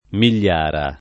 [ mil’l’ # ra ]